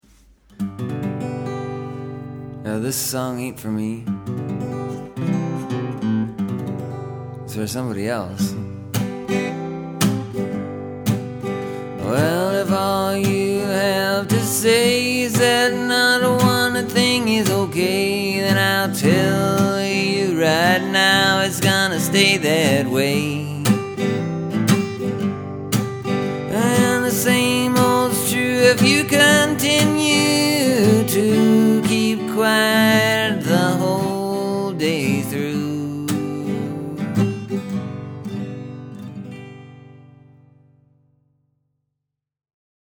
The music and melody just kinda turned into what all my other songs are starting to sound like anyway.